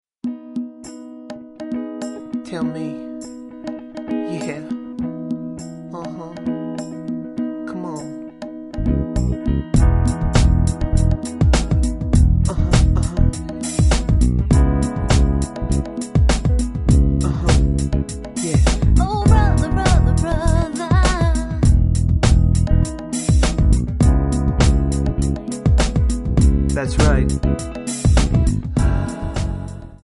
Audio Backing tracks in archive: 9793